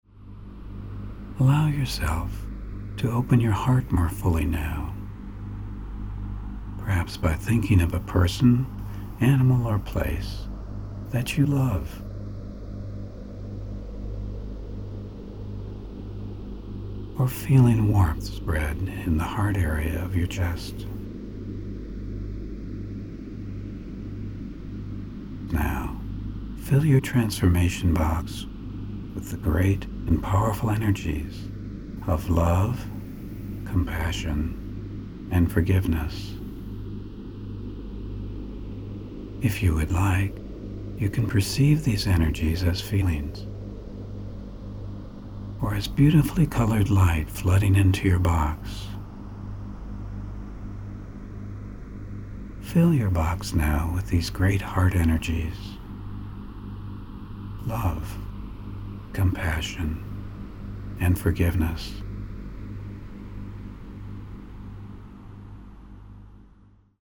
Zdokonalte a prohlubte své smysluplné vztahy pomocí tohoto verbálně vedeného cvičení Hemi-Sync®.